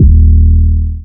DDW3 808 5.wav